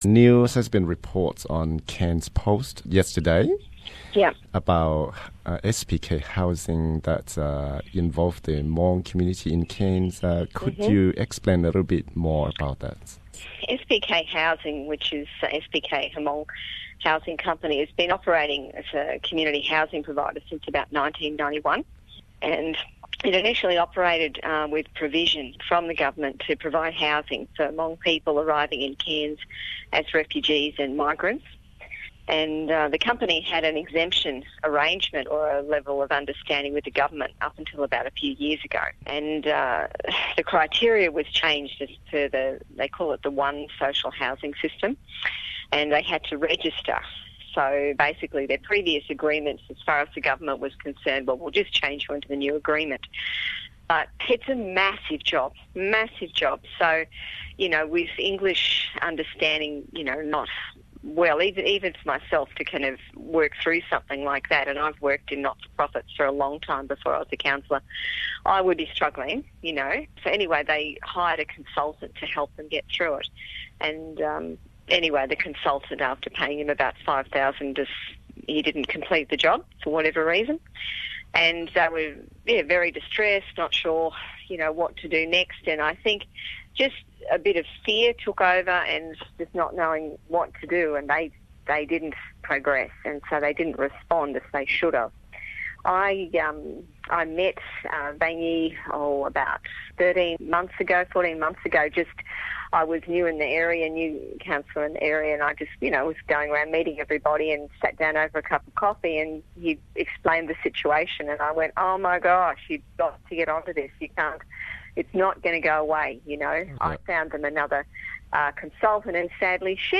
Cr. Cathy Zeiger tham txog cov teeb meem vaj tse SPK Housing uas muaj teeb meem rau cov cuab yig neeg Hmoob nyob Cairns (Interview in English)